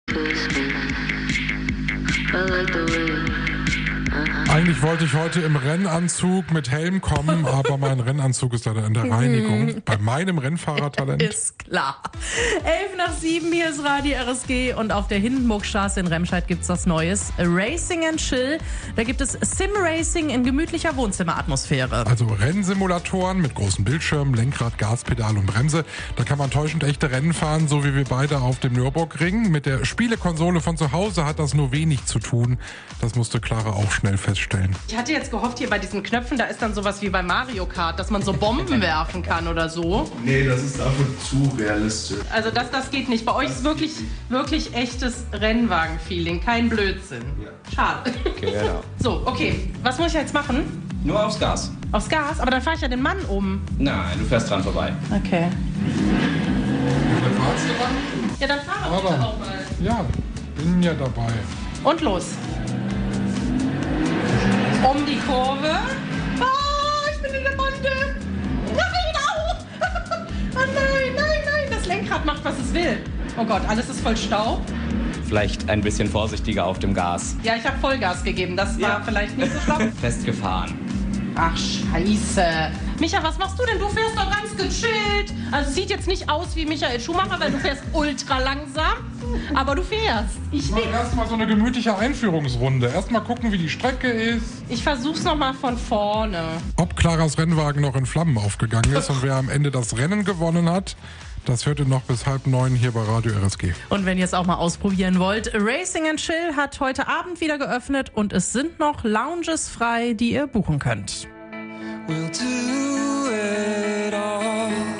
Unsere Moderatoren haben die E-Racing Simulatoren für euch getestet.